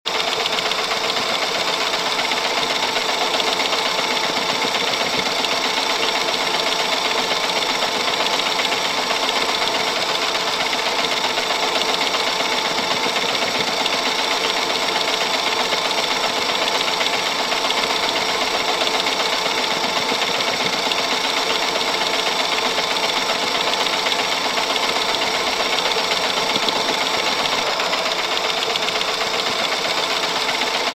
Camera-reel-sound-effect.mp3